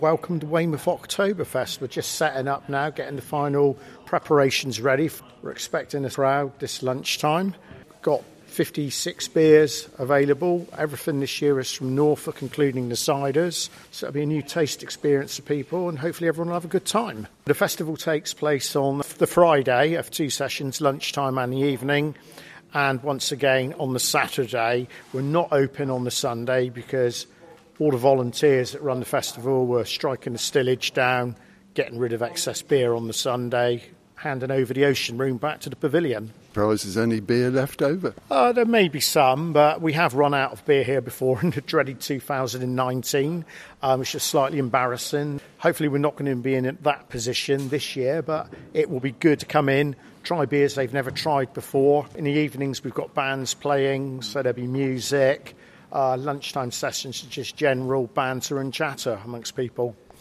West Dorset Campaign for Real Ale (CAMRA) is delighted to report on the huge success of Weymouth Octoberfest 2025 held at Weymouth Pavilion.